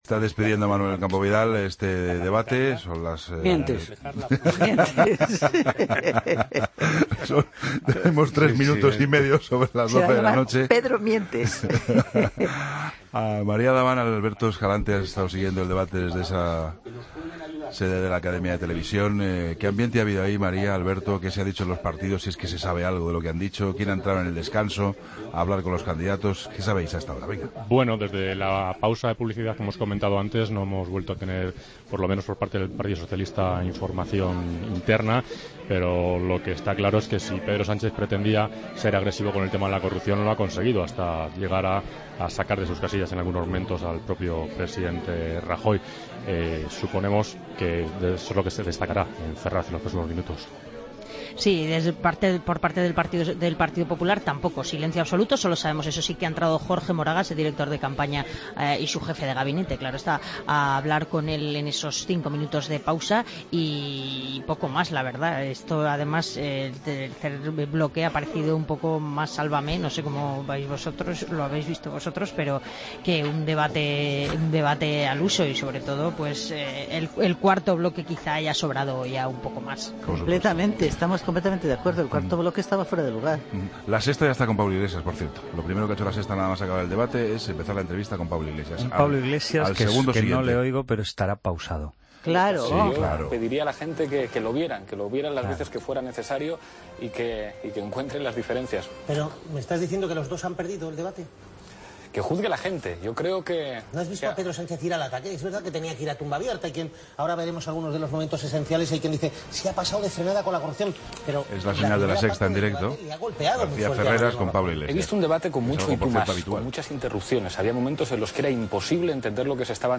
Tertulia en La Linterna: Debate cara a cara